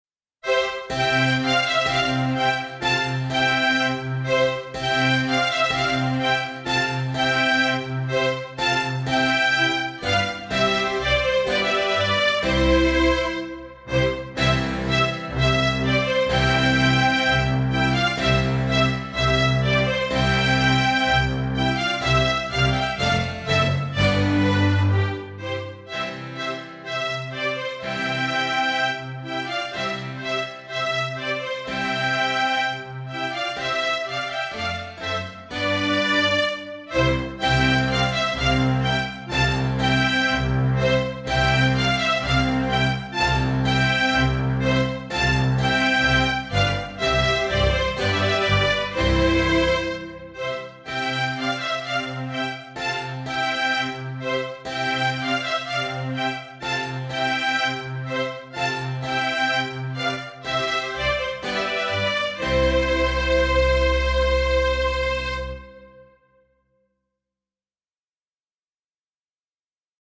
soundmix (langzamer dan op je cd)